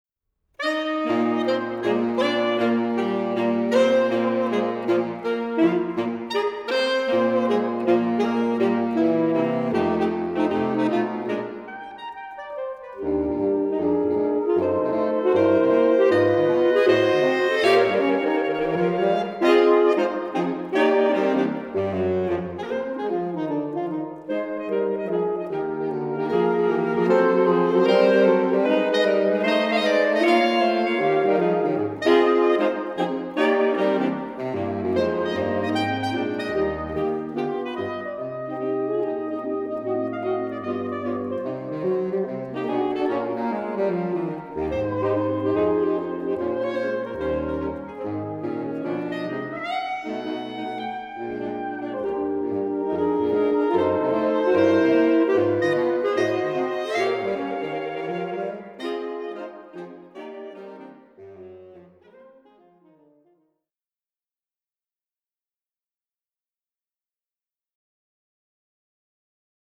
Saxophon
• Mit seinem wunderbar weichen Klang, seiner grossen Dynamik und Klangvielfalt ist es sehr vielseitig einsetzbar.
Tonbeispiel Saxquartett:
21-Saxophone.mp3